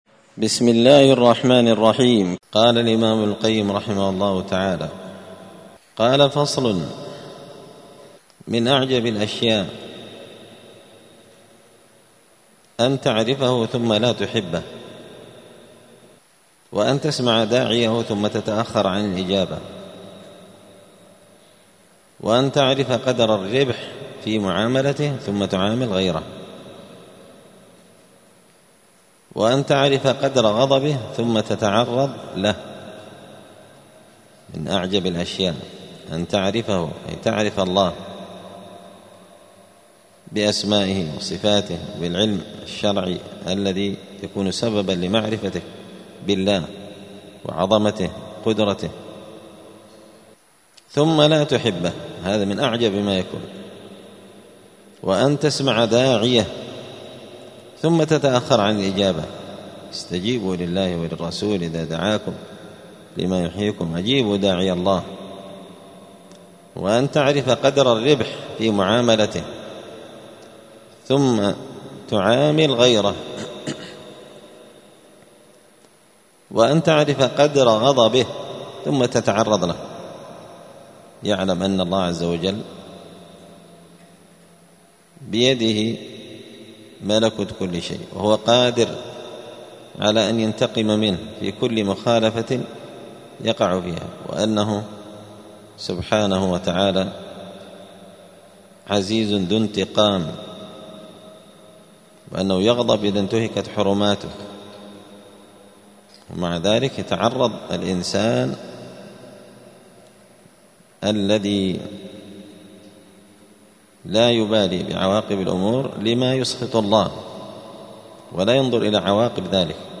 *الدرس العشرون (20) (فصل: من أعجب الأشياء أن تعرف الله ثم لا تحبه)*